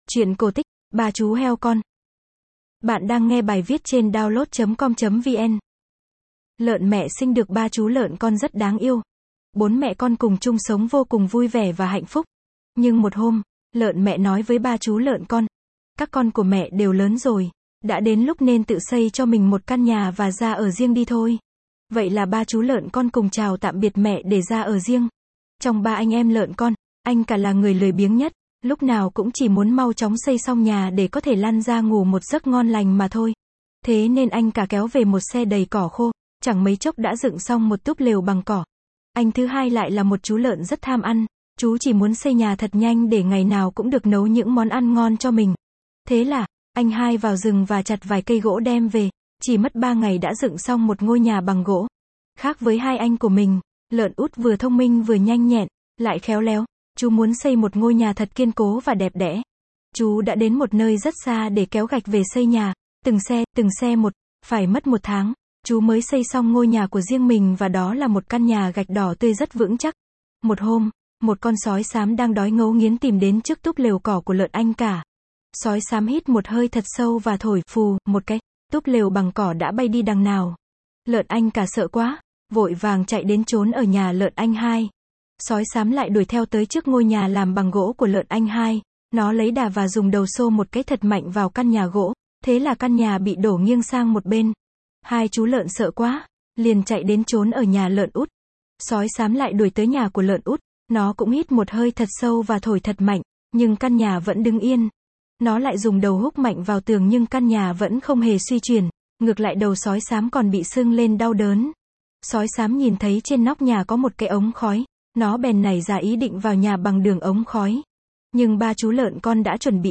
Sách nói | Ba chú heo con